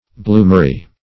Search Result for " bloomery" : The Collaborative International Dictionary of English v.0.48: Bloomery \Bloom"er*y\, n. (Manuf.) A furnace and forge in which wrought iron in the form of blooms is made directly from the ore, or (more rarely) from cast iron.